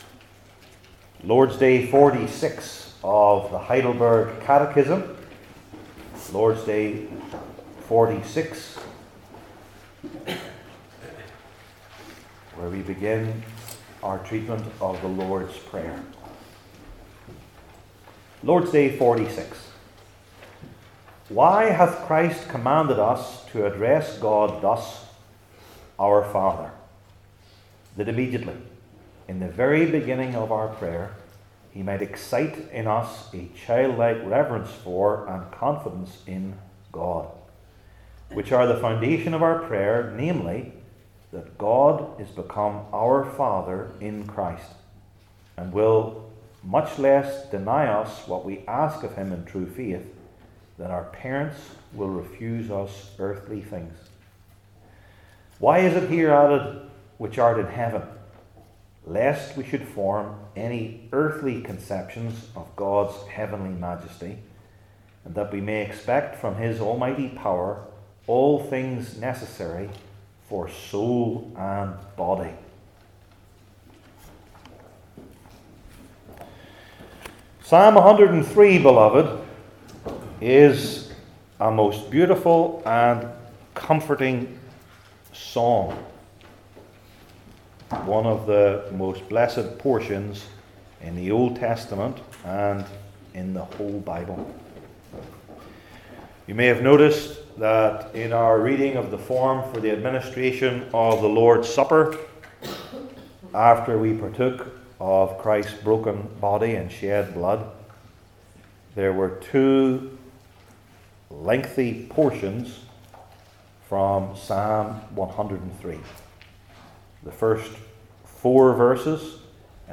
Heidelberg Catechism Sermons I. The Meaning of It II.